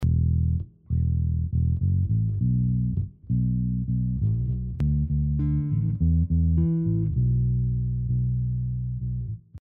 See attached audio example and screenshot. 2 parts of a bass track put in sequence, direct on the bar.
But as you can hear, in the transition there is a loud click.